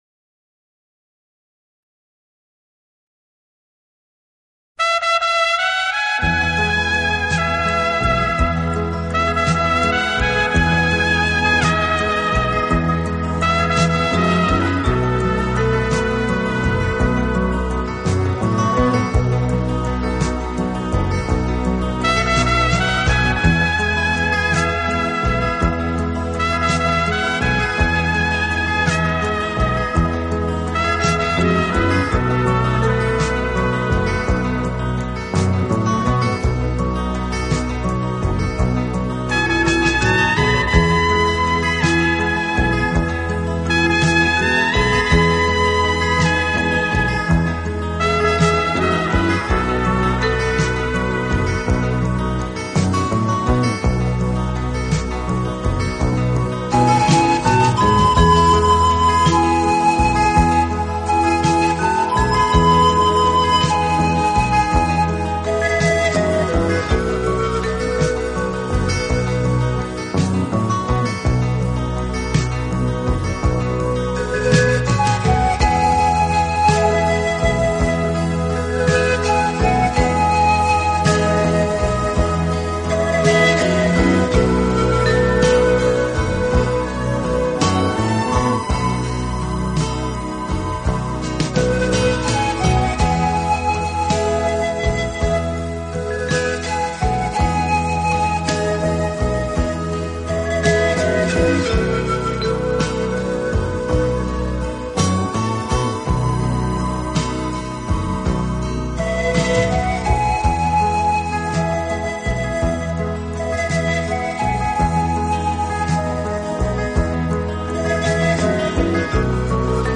同时其录音又极其细致、干净、层次分明，配器简洁明了，是近